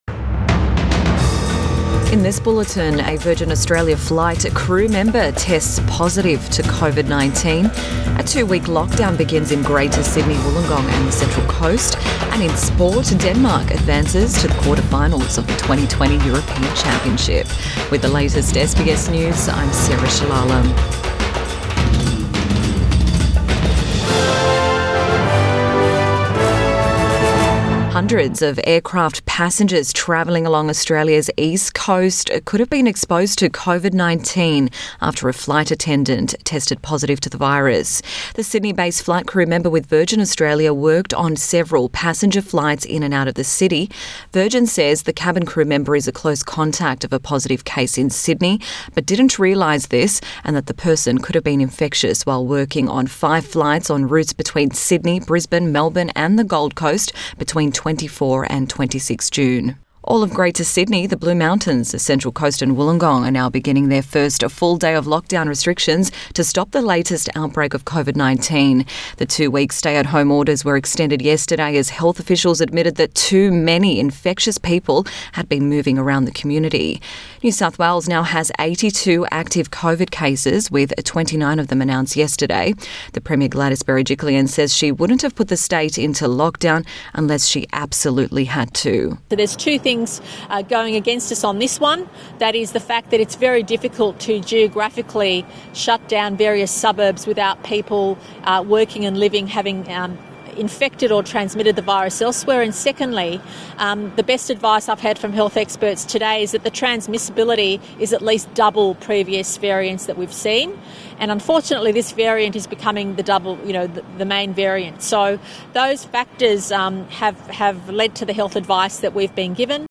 AM Bulletin 27 June 2021